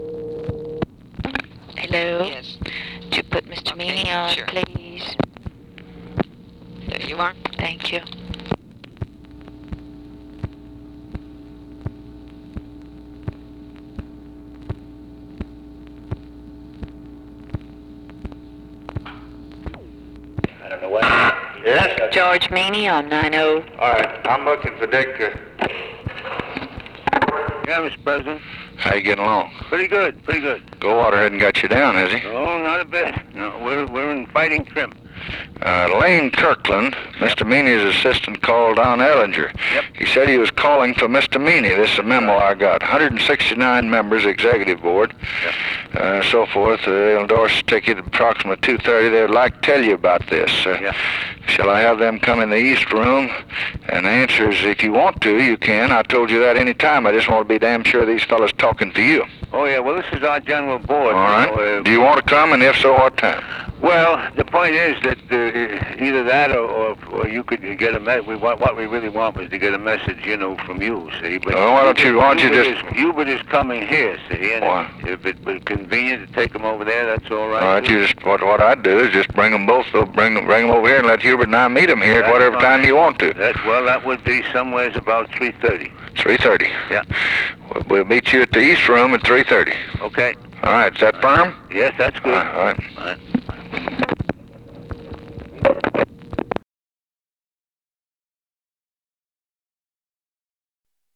Conversation with GEORGE MEANY, September 1, 1964
Secret White House Tapes